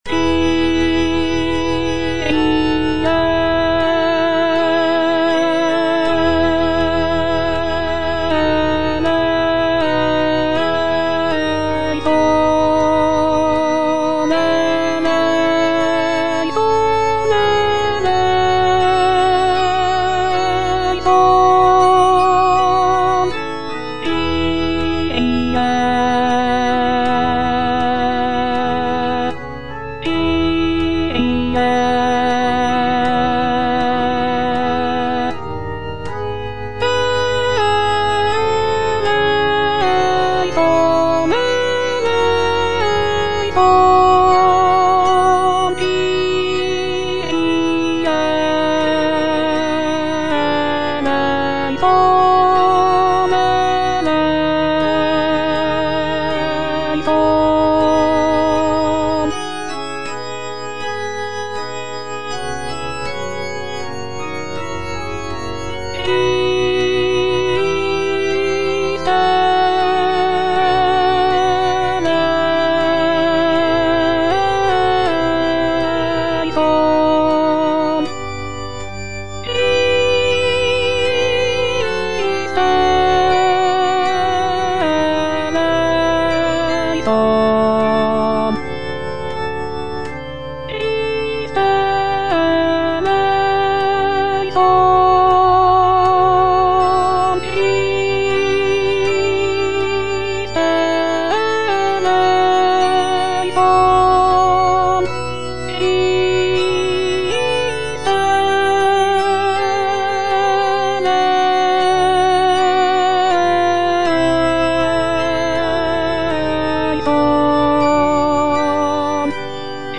J.G. RHEINBERGER - MISSA MISERICORDIAS DOMINI OP.192 Kyrie - Alto (Voice with metronome) Ads stop: auto-stop
Completed in 1887, it is a mass setting for mixed choir, soloists, and orchestra.